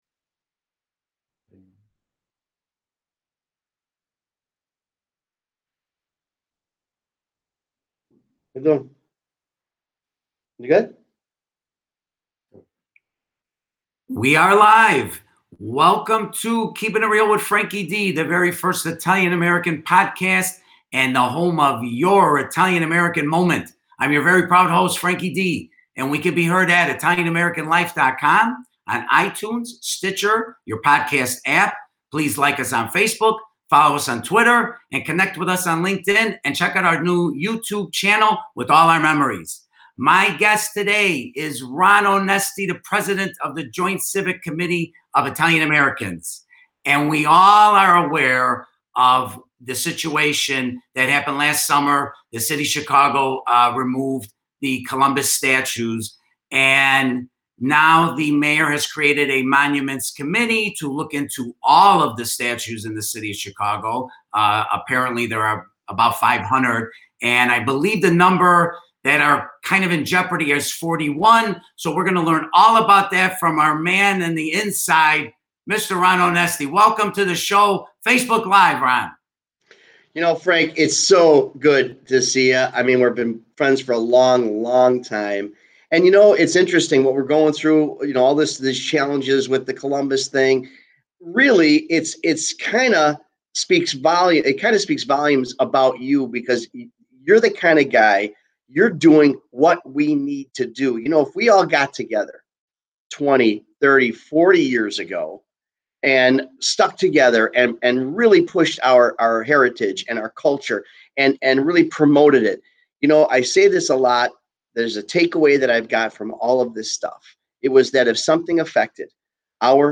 Interview
talk-show-enweu8_-kdg3.mp3